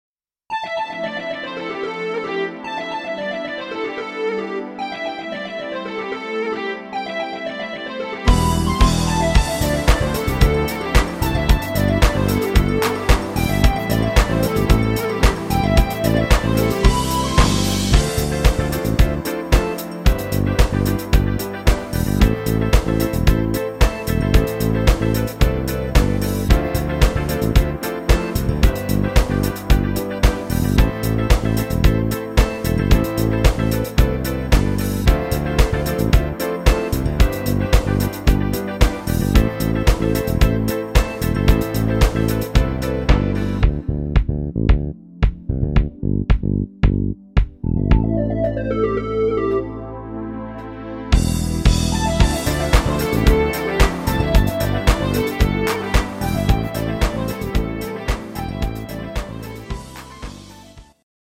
Rhythmus  8 Beat
Art  Englisch, Pop